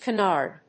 音節ca・nard 発音記号・読み方
/kənάɚd(米国英語), kˈænɑːd(英国英語)/